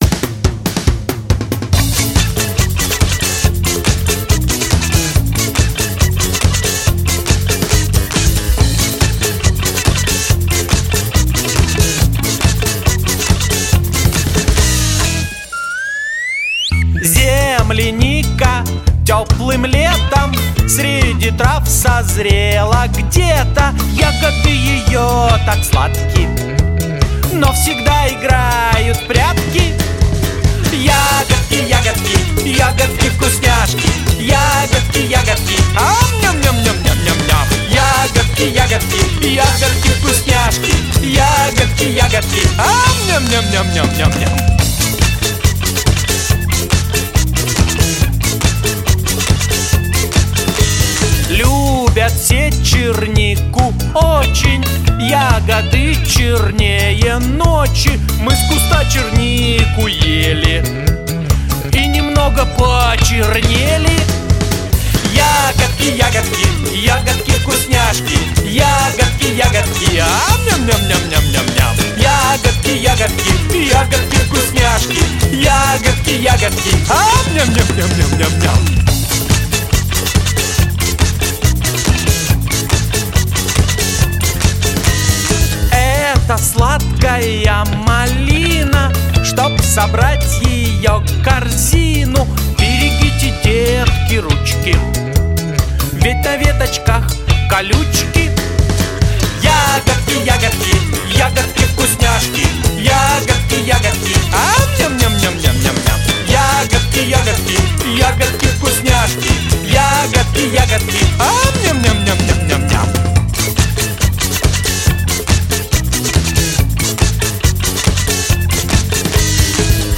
• Категория: Детские песни
малышковые, мультфильм